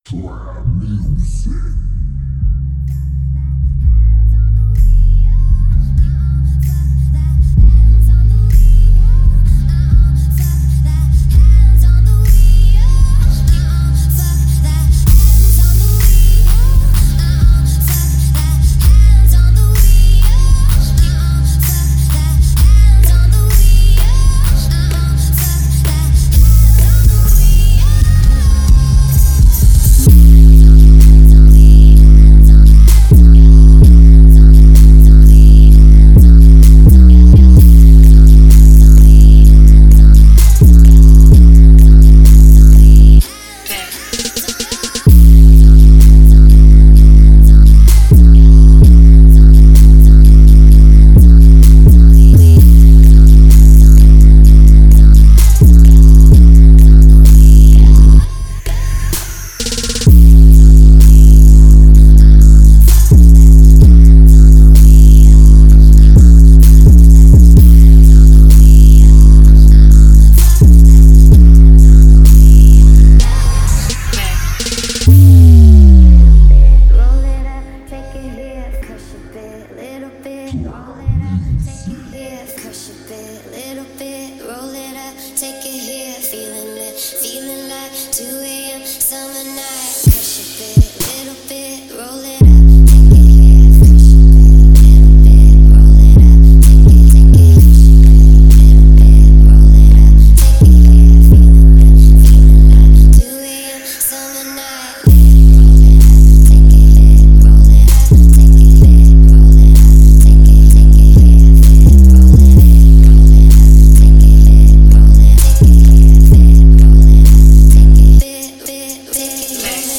?Bassboosted?_-_без_БУФЕРА_не_слушать
Bassboosted___bez_BUFERA_ne_slushatjq.mp3